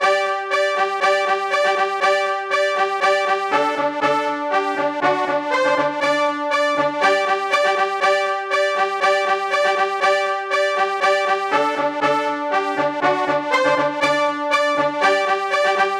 描述：dsk黄铜
Tag: 120 bpm Hip Hop Loops Brass Loops 2.70 MB wav Key : Unknown